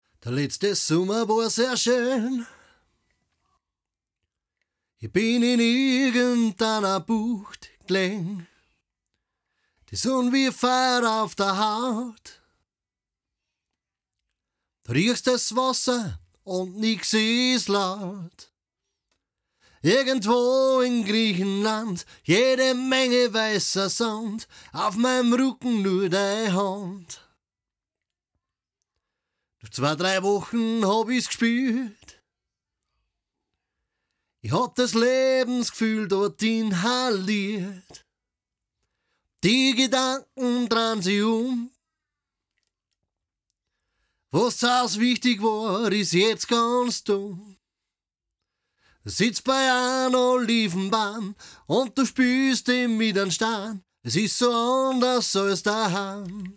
Gesangsaufnahmen klingen immer relativ harsch und spitz in den Mitten.
Klingt nach komischem, resonannten Raum.
Hab mal kurz 2min drüber gebügelt, musste schon etwas gröber EQen, damit es etwas füllig wird.